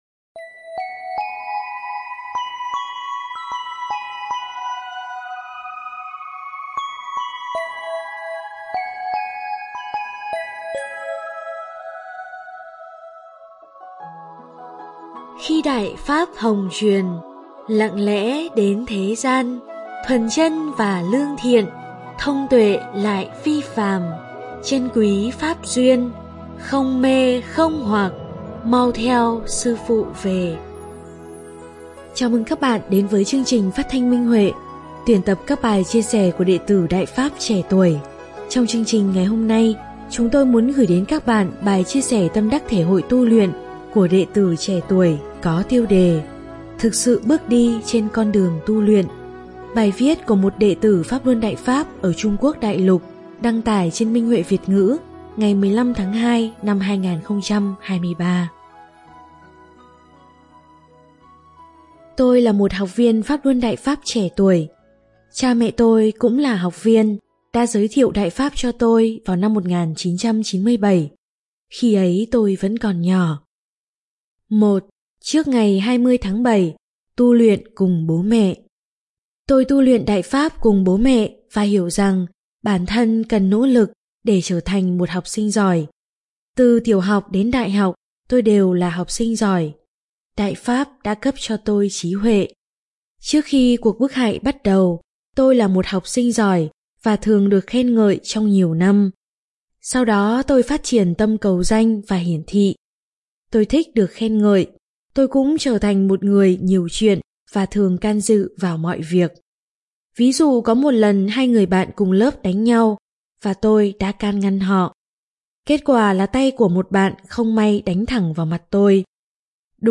Chào mừng các bạn đến với chương trình phát thanh Minh Huệ. Chương trình phát thanh ngày hôm nay xin gửi tới các bạn một số bài chia sẻ của các đệ tử Đại Pháp trẻ tuổi.